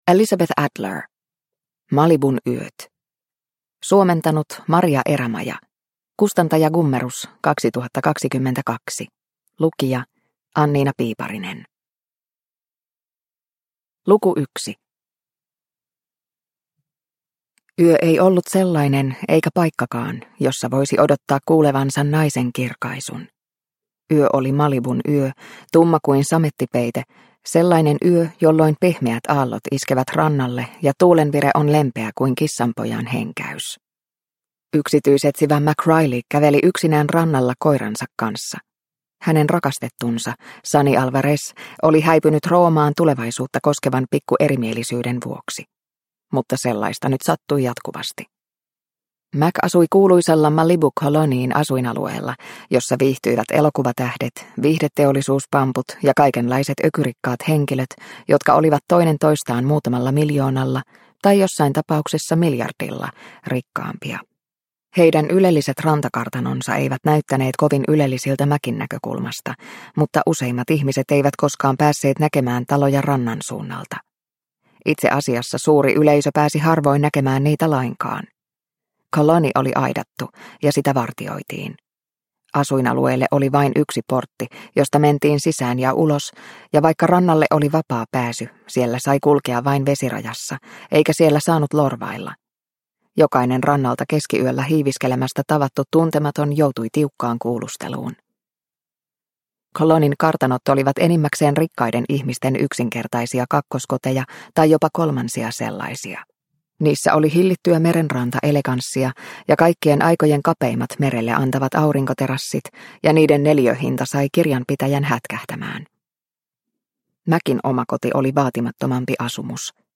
Malibun yöt – Ljudbok – Laddas ner